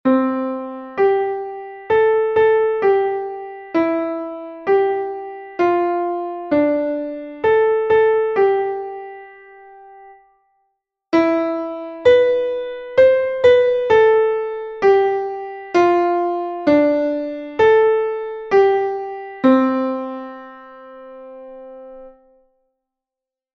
Exercise 1 5th interval practice
7._melodic_reading_practice.mp3